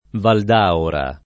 [ vald # ora ]